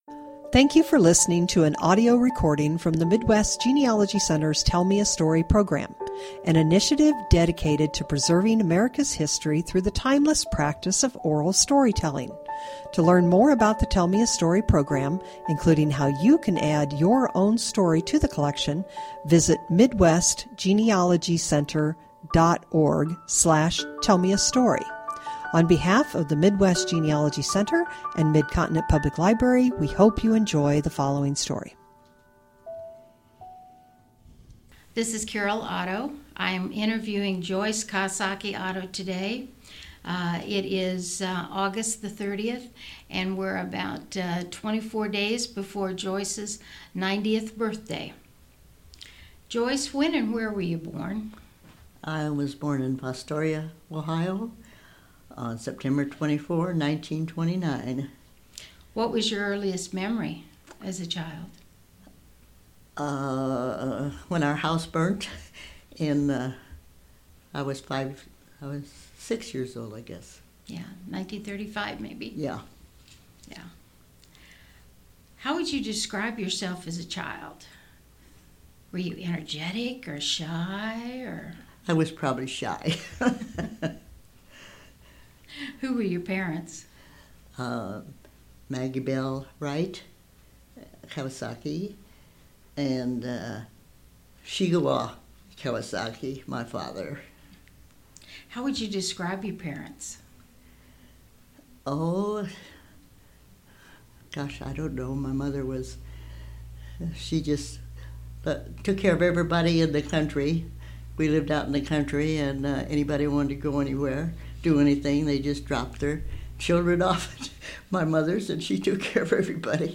Oral History Genealogy Family History